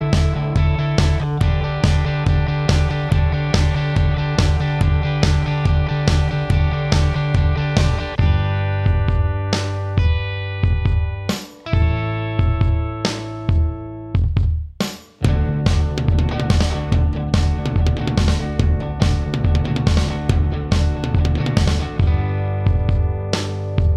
No Lead Guitar Rock 2:57 Buy £1.50